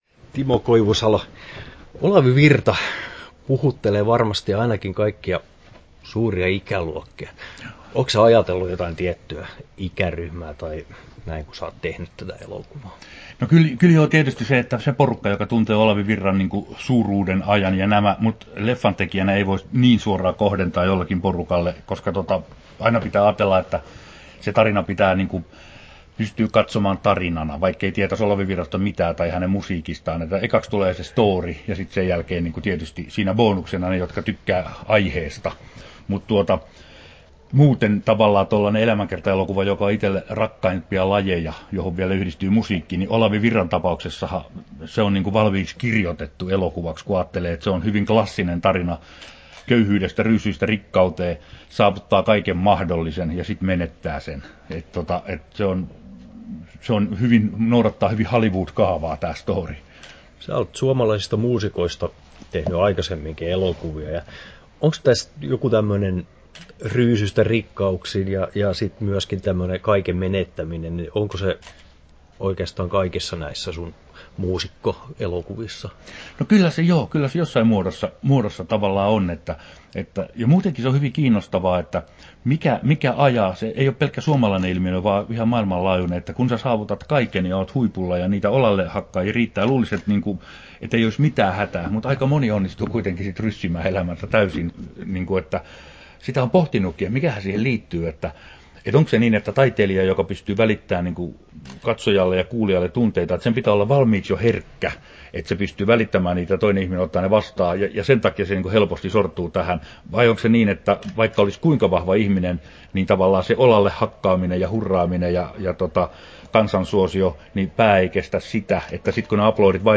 Haastattelussa Timo Koivusalo Kesto: 9'00" Tallennettu: 20.09.2018, Turku Toimittaja